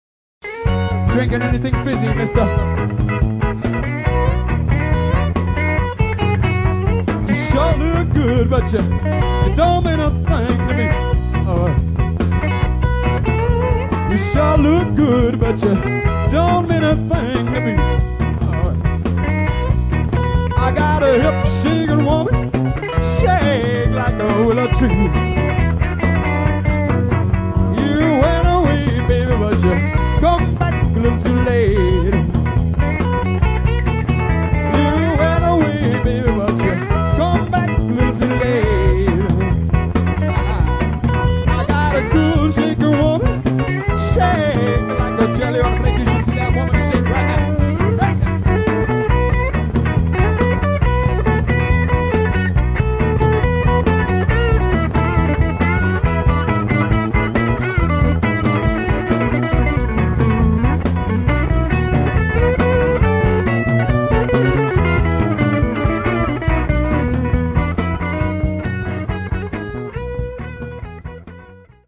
Classic live recordings from our vast back catalogue.